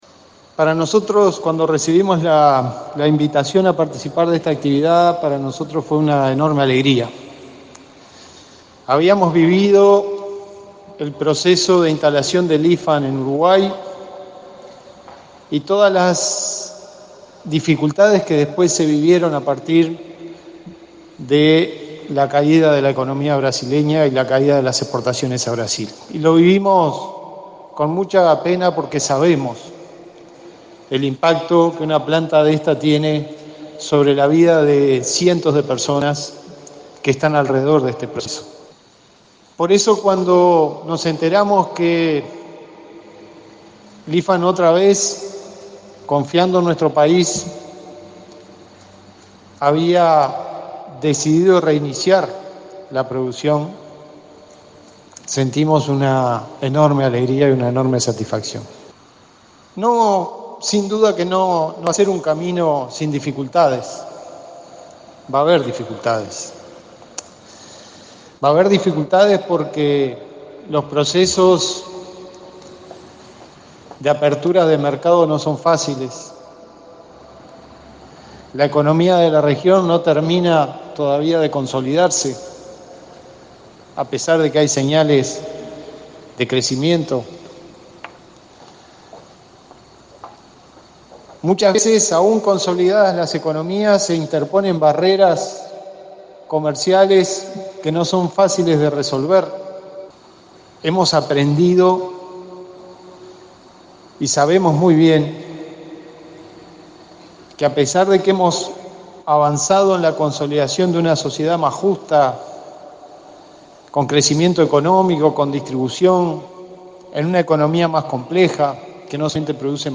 El vicepresidente Raúl Sendic participó este viernes de la reinauguración de la planta de Lifan en Uruguay. En ese marco, advirtió que los procesos de apertura de mercados no son fáciles y la economía de la región no termina de consolidarse a pesar de que hay señales de crecimiento.